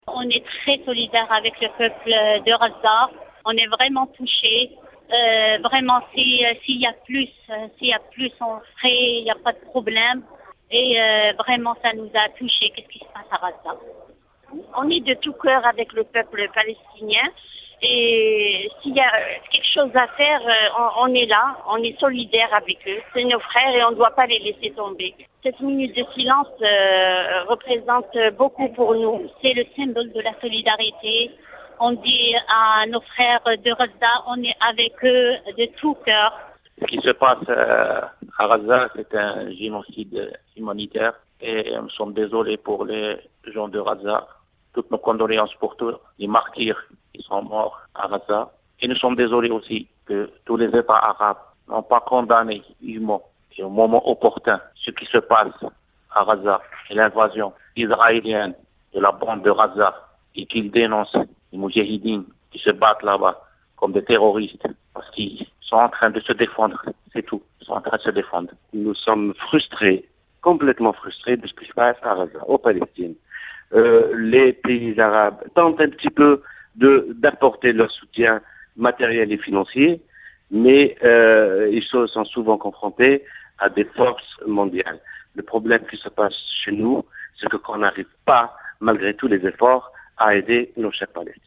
Témoignages des Algériens à travers le pays